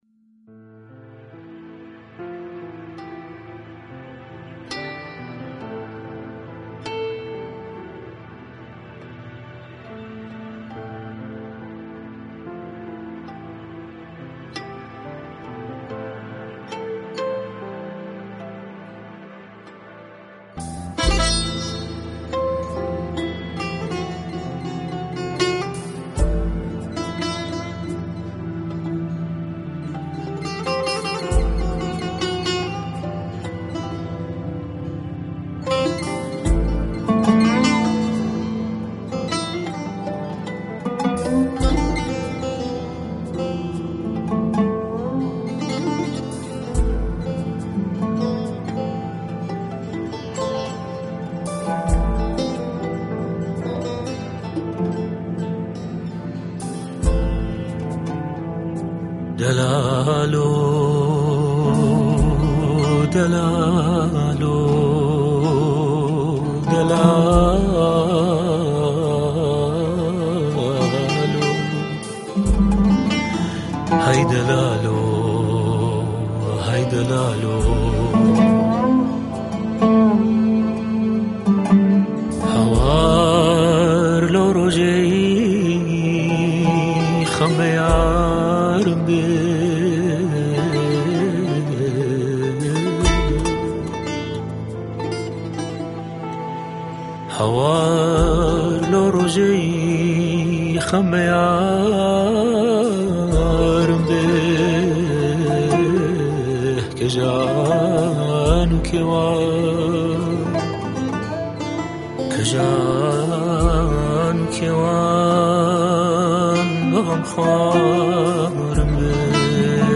یك شعر فولك كردی را اجرا كرده است.